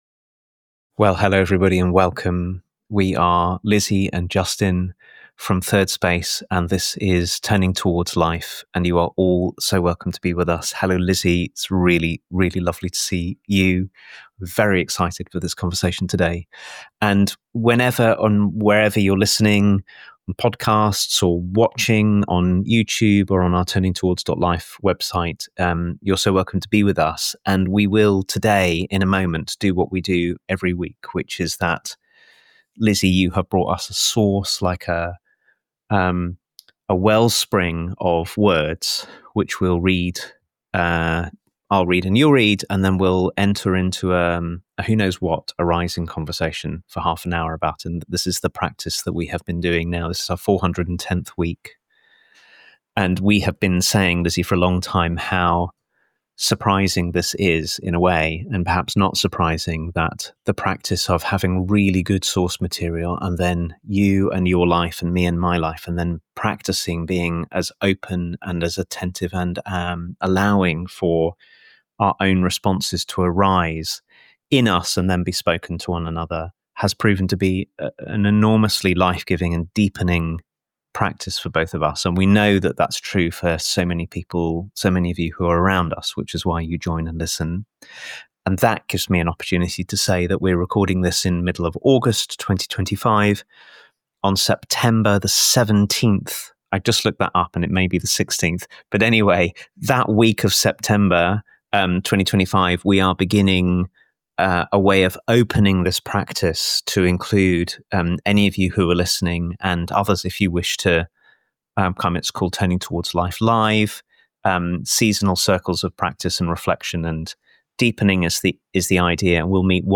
How do we attend to the something in each of us that longs to break free through the cracks of our self-image? A conversation about discovering that sacred uniqueness within ourselves and others in the everyday mundane acts of living, loving, working and being genuinely presen...